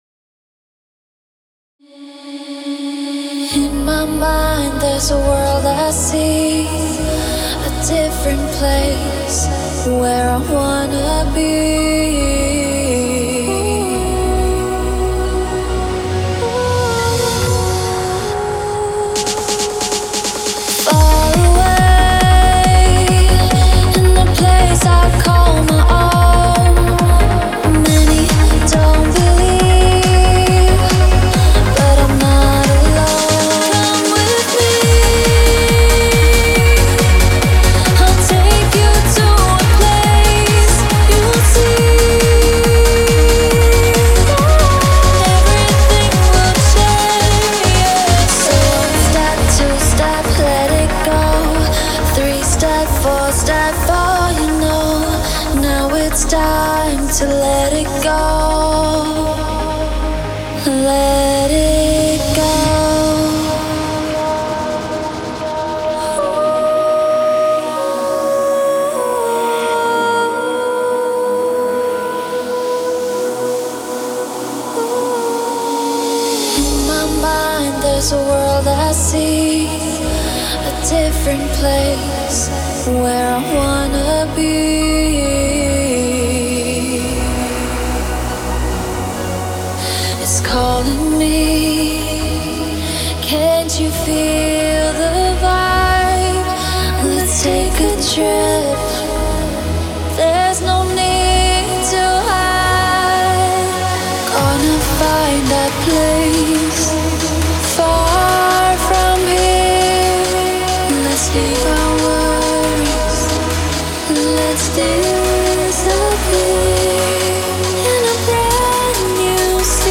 Beim Mixen und Mastern bin ich eher fortgeschrittener Einsteiger, aber es geht in die Richtung, die ich anstrebe.